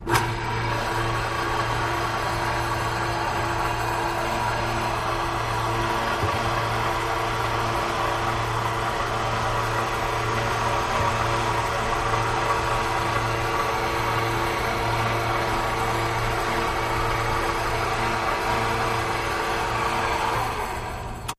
Space Door, Electric, Open